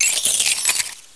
direct_sound_samples / cries
uncomp_klefki.aif